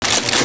Weapons